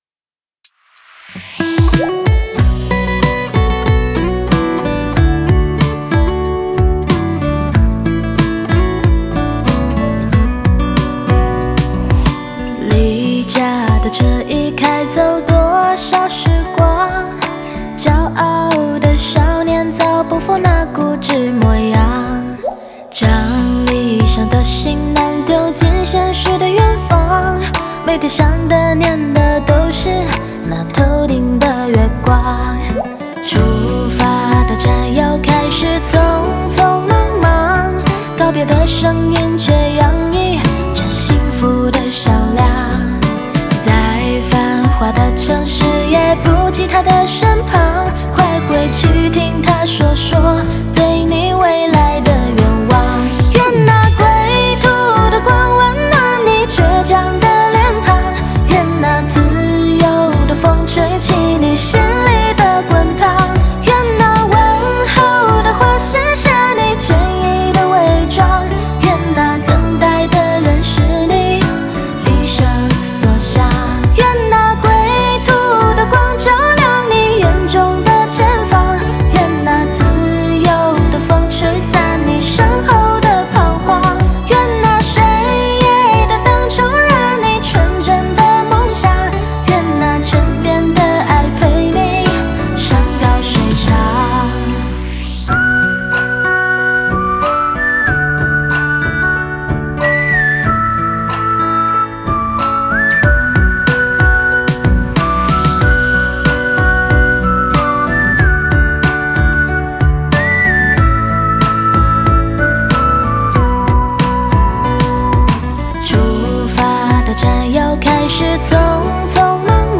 Ps：在线试听为压缩音质节选，体验无损音质请下载完整版
吉他 Guitar